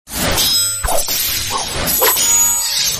Sword Clash
# sword # metal # clash About this sound Sword Clash is a free sfx sound effect available for download in MP3 format.
016_sword_clash.mp3